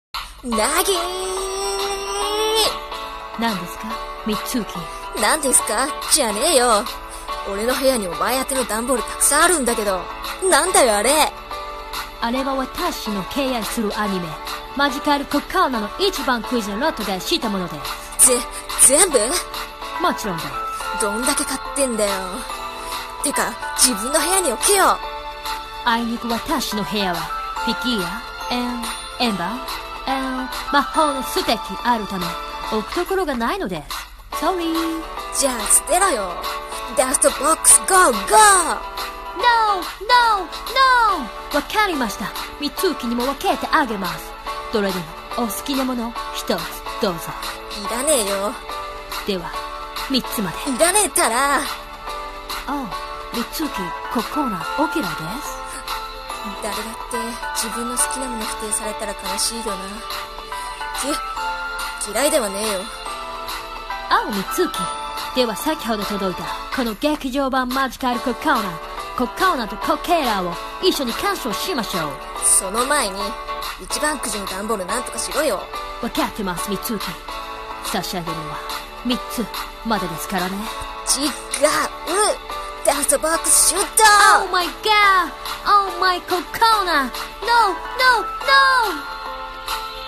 【アイナナ声劇】Oh!my ここな☆